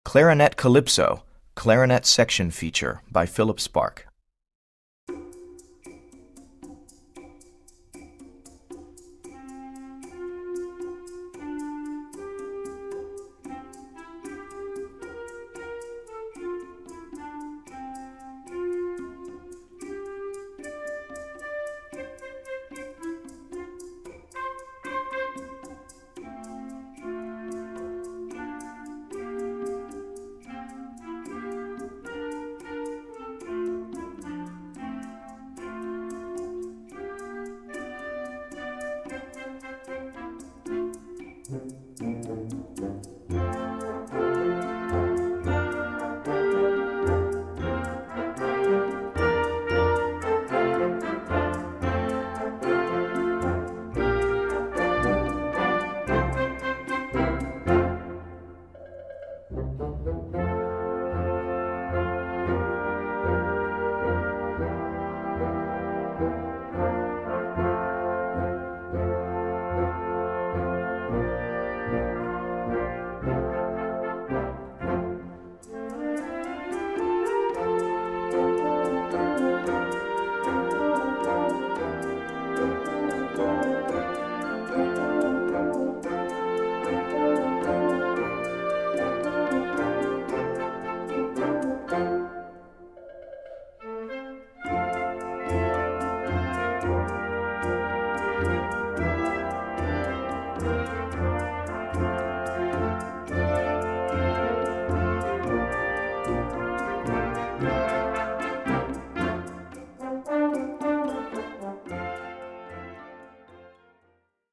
Voicing: Clarinet Section w/ Band